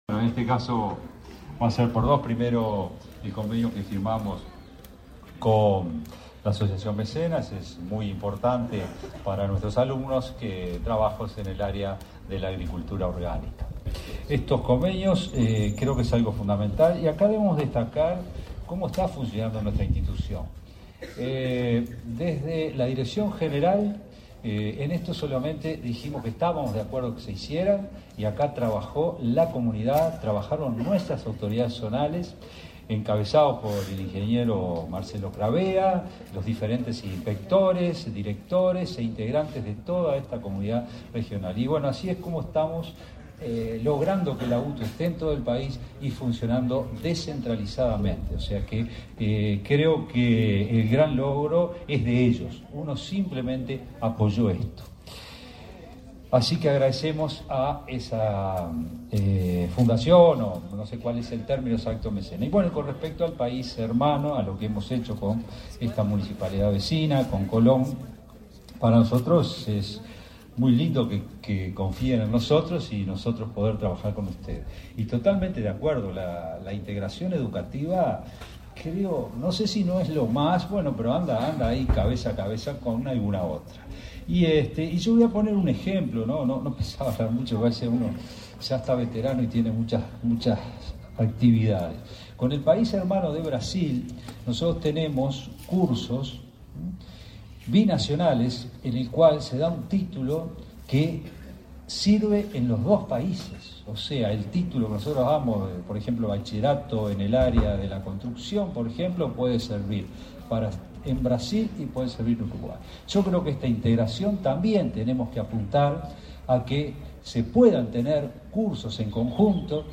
Acto por la firma de convenios de ANEP y UTU en Paysandú